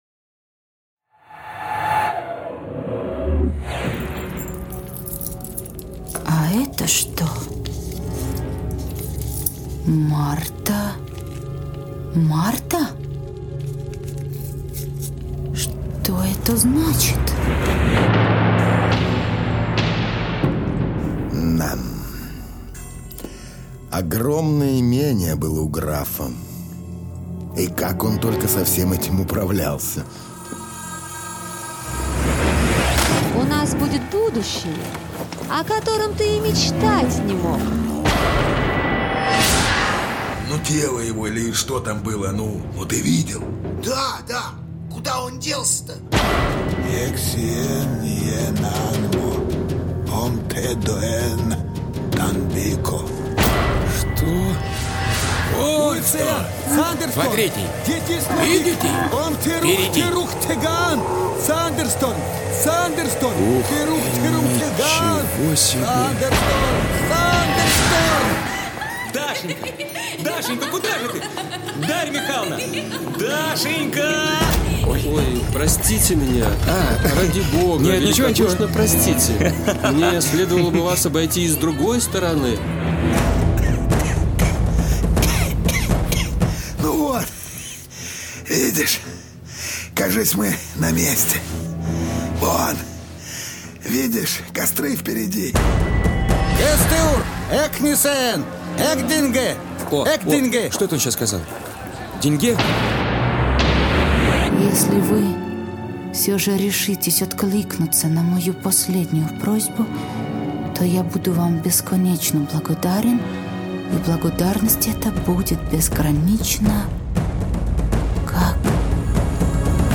Аудиокнига Сделка. Тайна Графа (3-й сезон, серии 17-24) | Библиотека аудиокниг